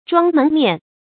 装门面 zhuāng mén miàn
装门面发音
成语注音 ㄓㄨㄤ ㄇㄣˊ ㄇㄧㄢˋ